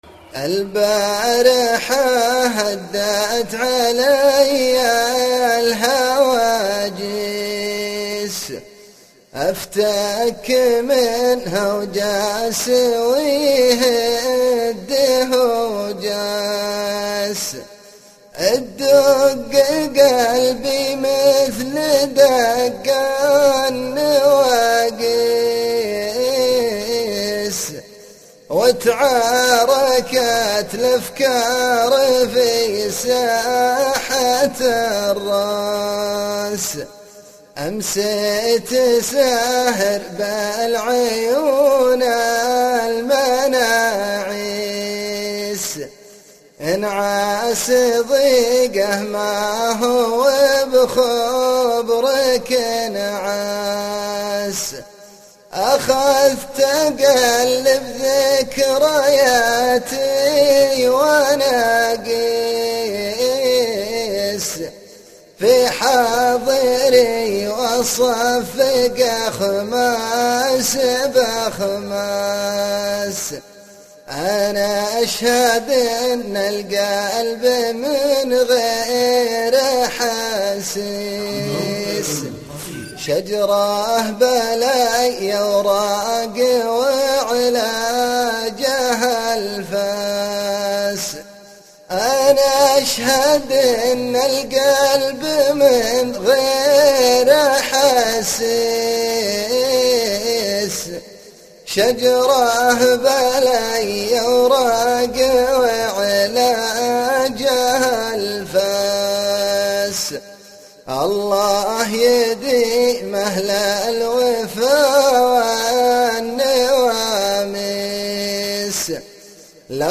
شيله